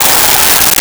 Tire Screech
Tire Screech.wav